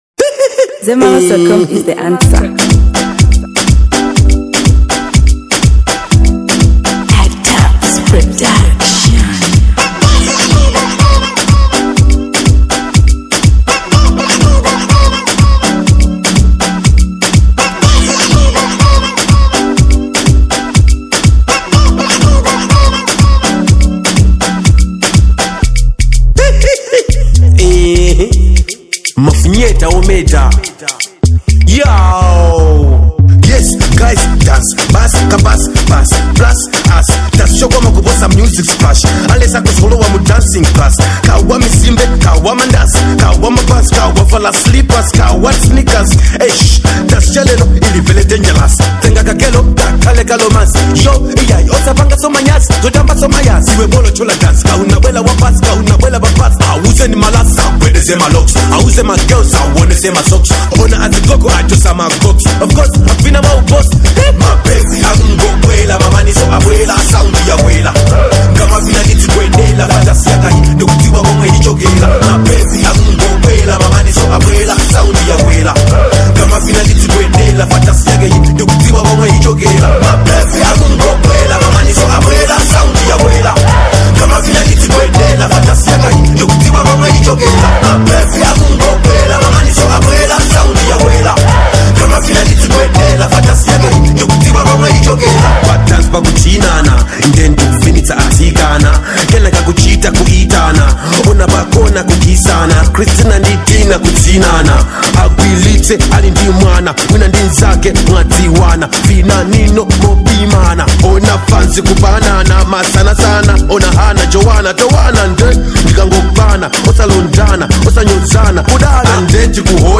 Dancehall • 2025-09-15